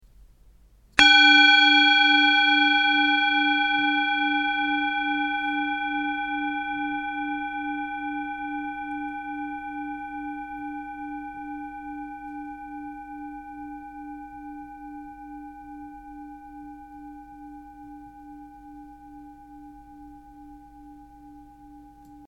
Indische Assam Klangschale - SATURN + AMOR
Grundton: 296,08 Hz
1. Oberton: 812,94 Hz
M78-456g-Klangschalen.mp3